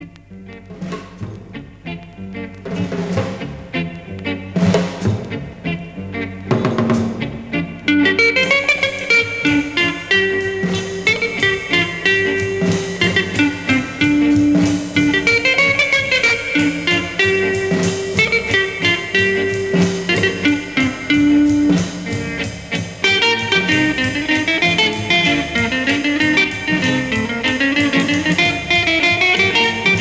Rock jazz